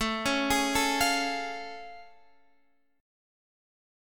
A+M7 chord